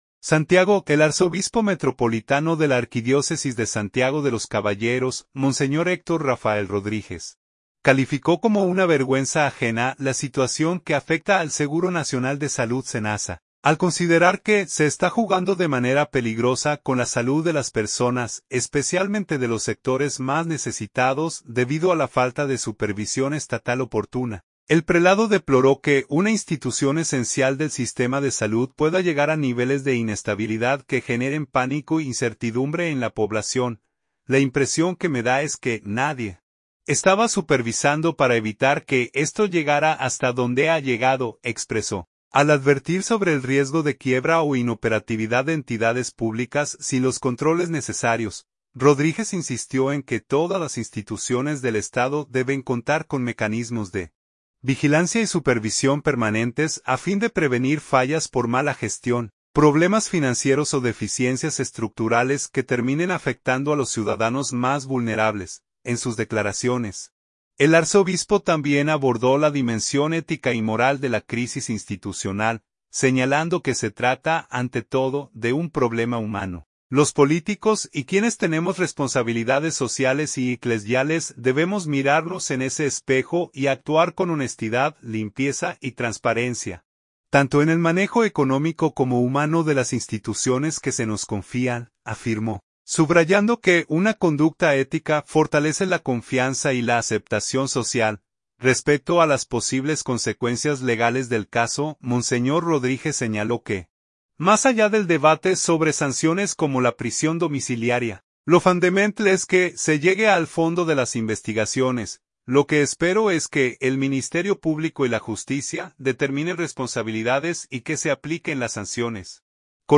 Las declaraciones fueron ofrecidas tras oficiar una misa de acción de gracias por un nuevo aniversario del Instituto Oncológico Regional del Cibao, ocasión en la que reiteró la necesidad de proteger, fortalecer y supervisar adecuadamente las instituciones de salud que brindan servicios a la población más necesitada.